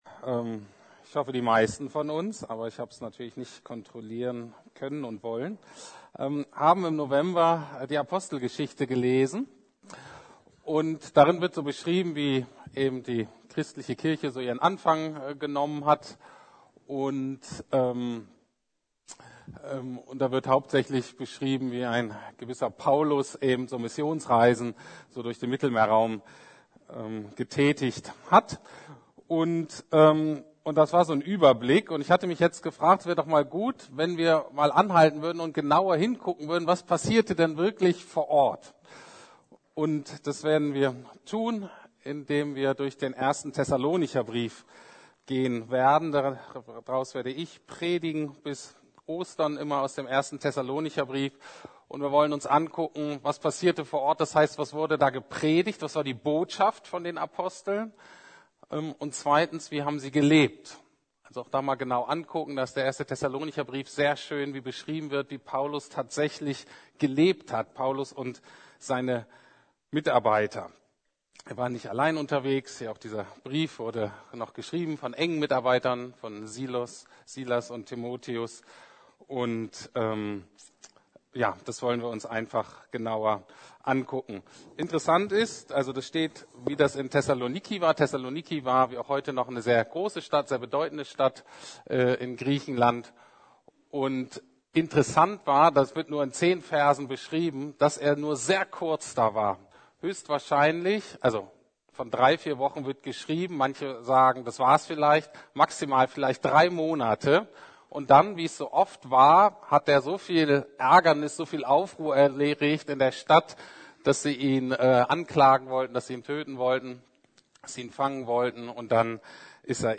Echte Veränderung - mehr als gute Vorsätze! ~ Predigten der LUKAS GEMEINDE Podcast